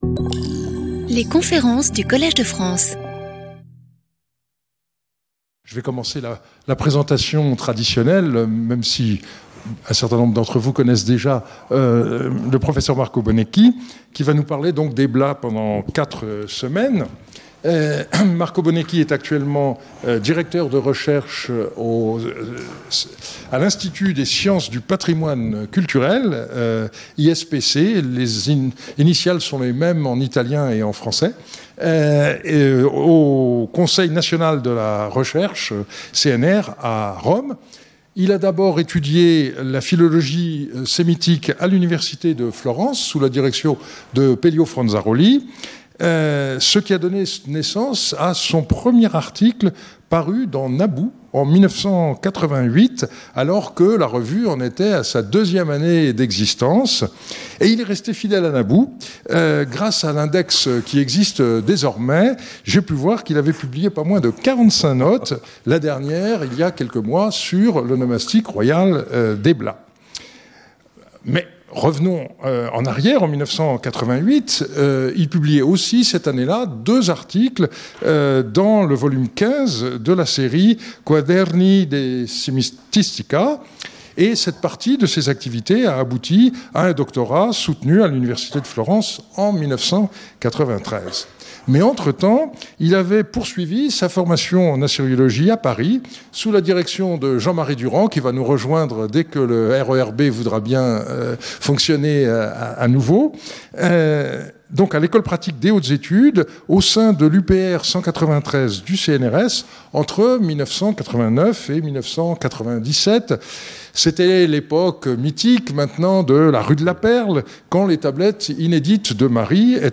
Lecture audio
Conférencier invité